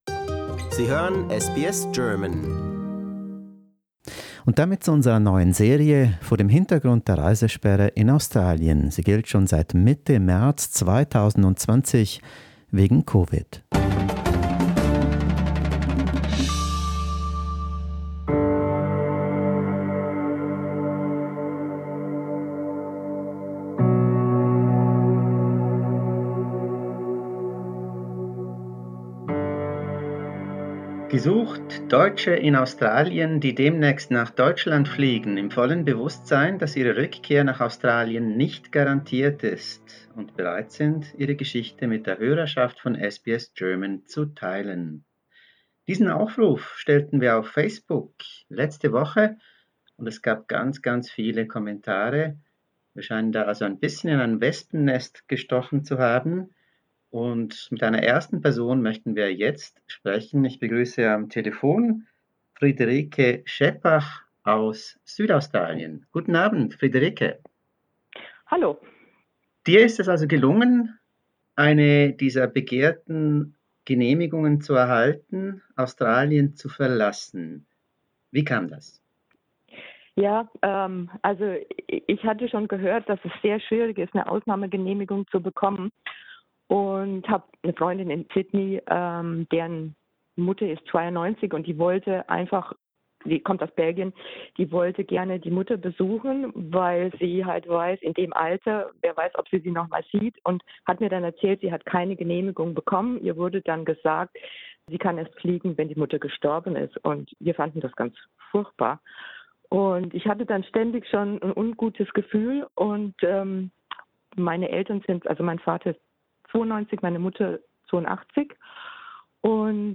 Grund sind die COVID-bedingten Reisebeschränkungen der Regierung und das Beweismaterial, das vorgelegt werden muss, um die "travel ban exemption" zu erhalten. Dazu ein Gespräch mit einer bayrischen Migrantin auf der Fleurieu-Halbinsel südlich von Adelaide.